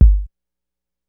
Kicks
BUMP_KIK.WAV